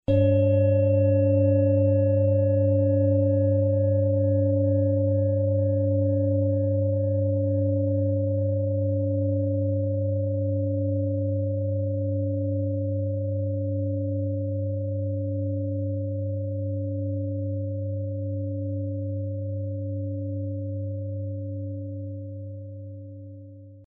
Planetenton
Tageston
Unter dem Artikel-Bild finden Sie den Original-Klang dieser Schale im Audio-Player - Jetzt reinhören.
MaterialBronze